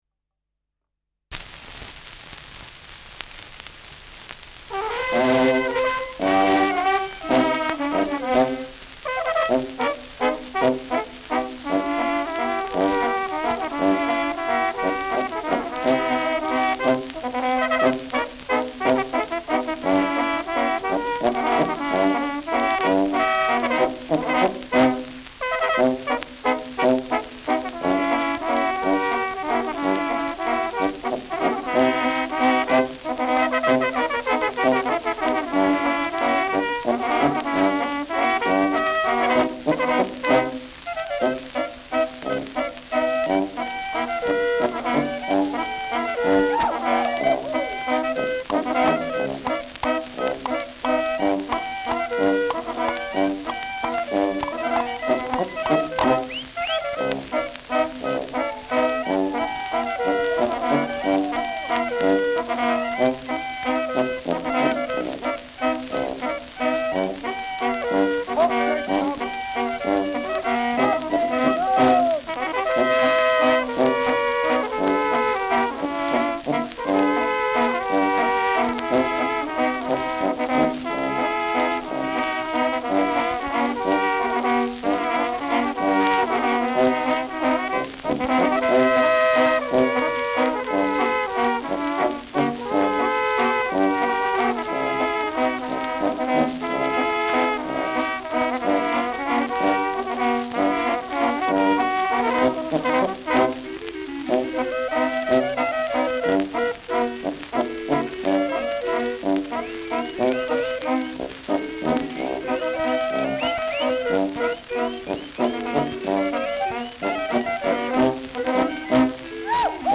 Schellackplatte
Tonrille: graue Rillen : leichte Kratzer durchgängig
leichtes Rauschen
Bauernkapelle Salzburger Alpinia (Interpretation)